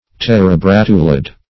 Search Result for " terebratulid" : The Collaborative International Dictionary of English v.0.48: Terebratulid \Ter`e*brat"u*lid\, n. (Zool.)